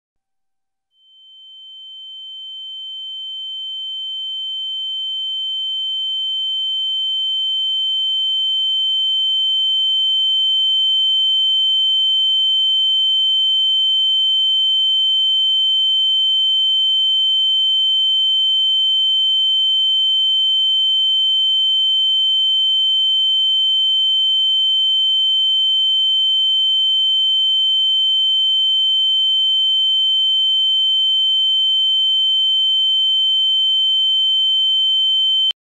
Ear Ringing Sound Effect - Free Download HD.mp3